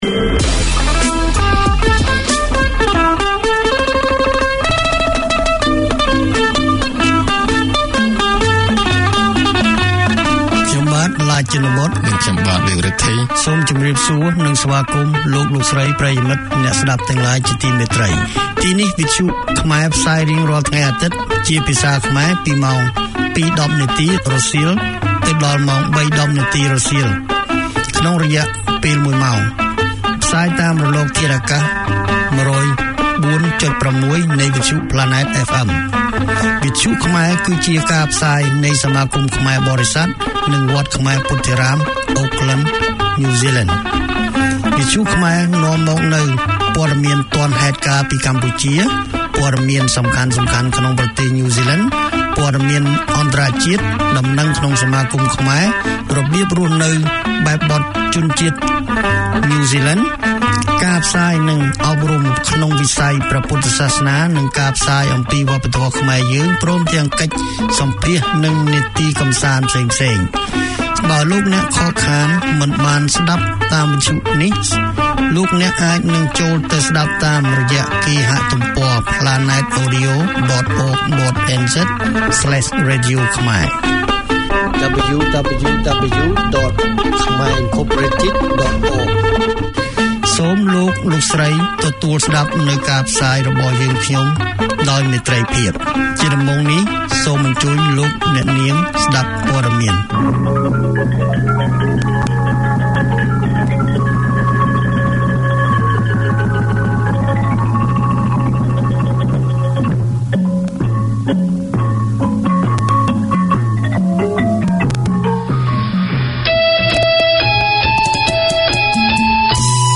Khmer news, news in NZ, guest interviews and talkback, community notices, orientation for Cambodian migrants to the Kiwi way, Buddhist Dharma teaching and cultural topics